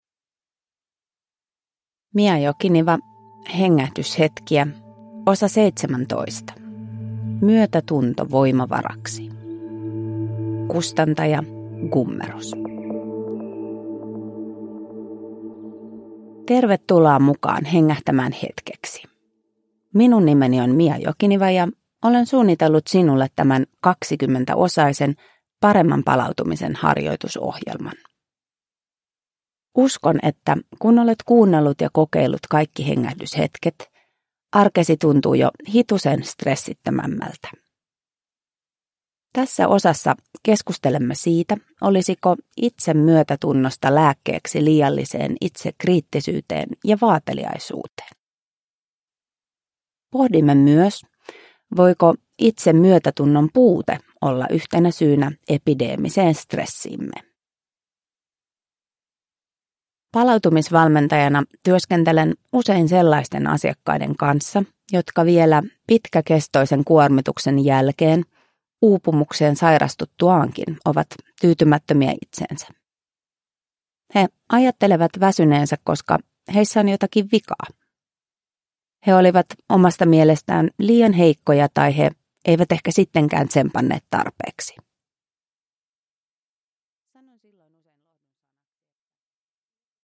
Hengähdyshetkiä (ljudbok)